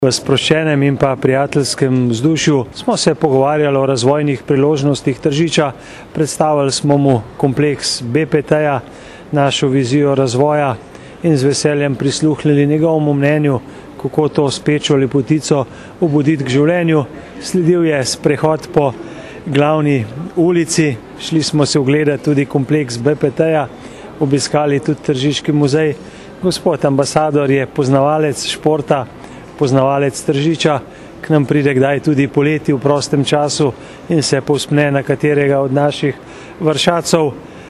izjavazupanaobcinetrzicmag.borutasajovica.mp3 (910kB)
75046_izjavazupanaobcinetrzicmag.borutasajovica.mp3